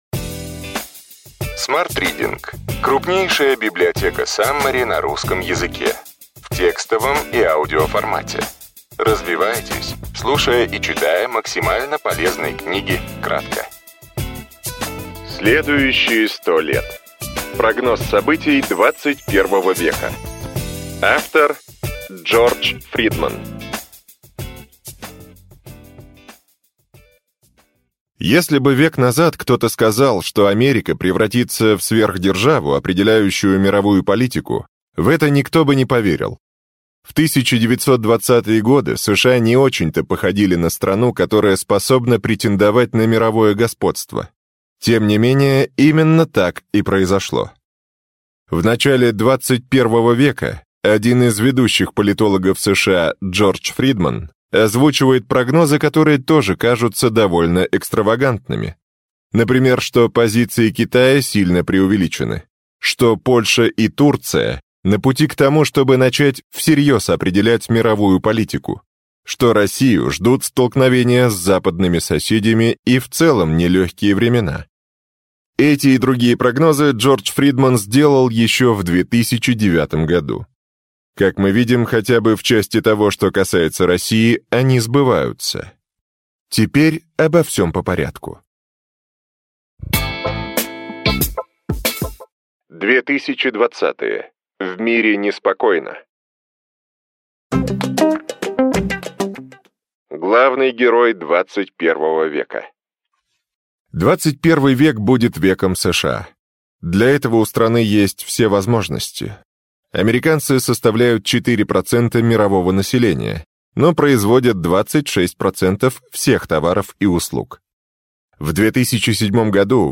Аудиокнига Ключевые идеи книги: Следующие 100 лет. Прогноз событий XXI века.